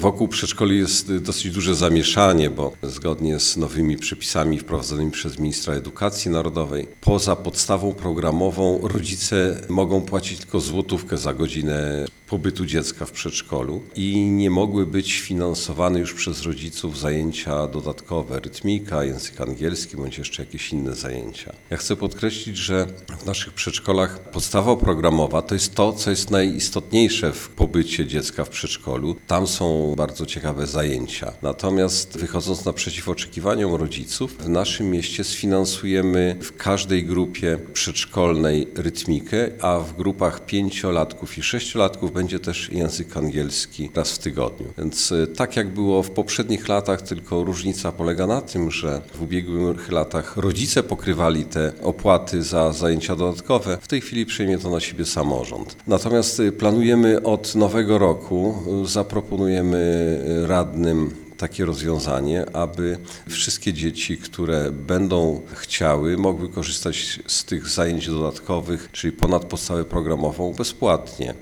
- Postanowiliśmy dzieciom z miejskich przedszkoli sfinansować rytmikę i zajęcia z języka angielskiego - informuje burmistrz Krasnegostawu Andrzej Jakubiec.